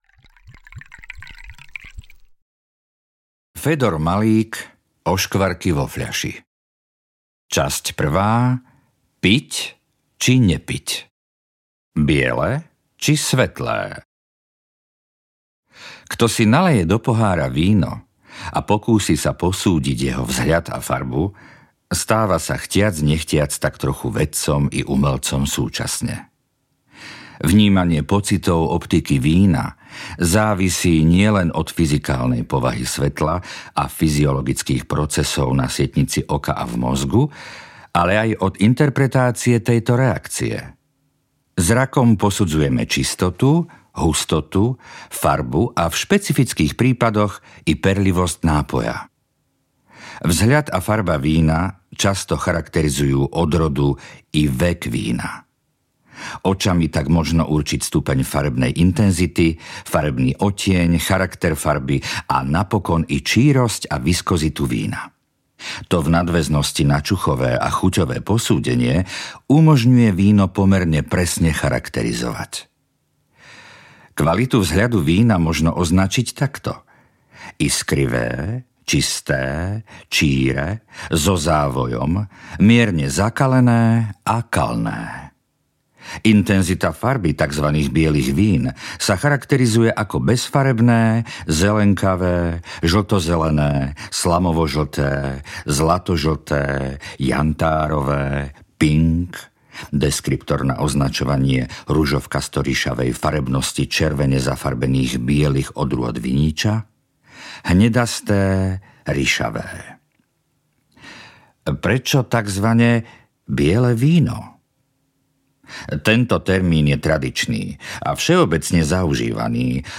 Oškvarky vo fľaši audiokniha
Ukázka z knihy
oskvarky-vo-flasi-audiokniha